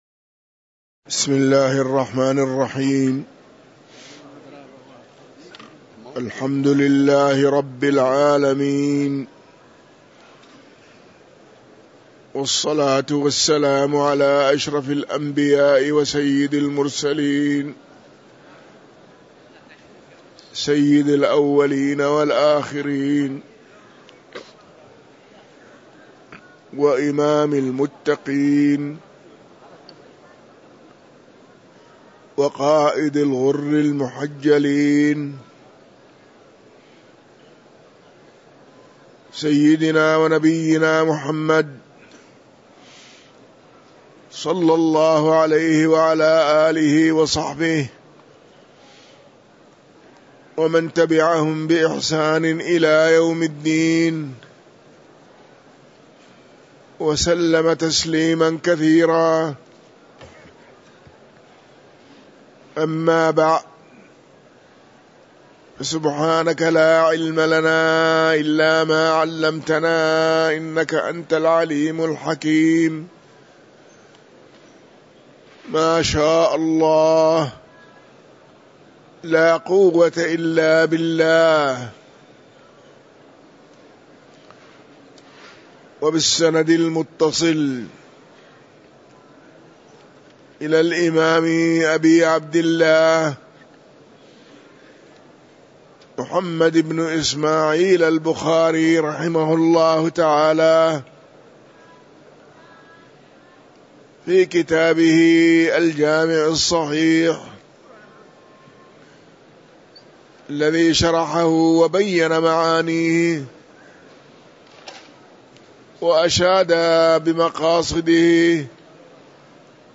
تاريخ النشر ١٠ جمادى الآخرة ١٤٤٤ هـ المكان: المسجد النبوي الشيخ